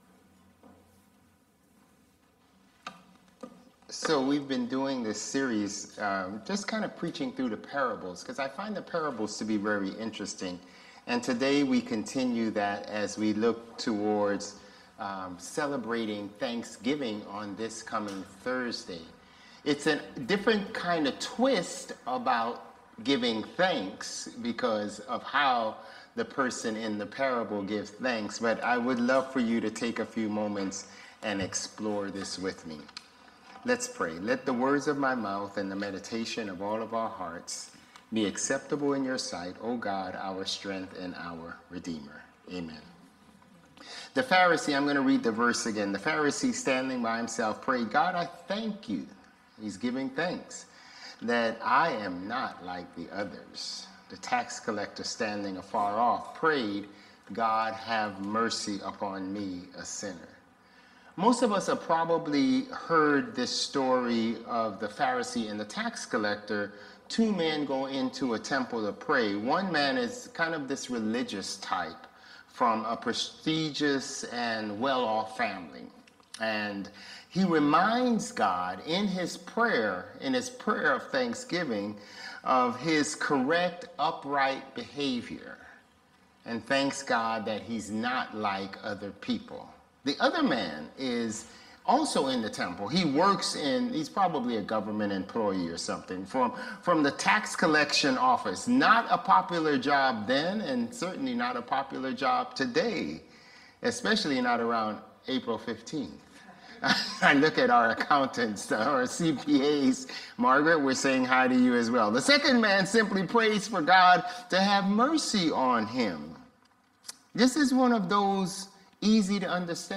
November 24 Worship
This sermon explores the parable of the Pharisee and the Tax Collector, focusing on the dangers of pride and self-righteousness in contrast to genuine humility before God. It emphasizes the importance of maintaining right relationships with both God and fellow humans, warning against the tendency to separate oneself from others through judgmental attitudes. The sermon challenges listeners to examine their own hearts, recognize their need for God's mercy, and cultivate a spirit of gratitude that doesn't stem from comparing oneself to others.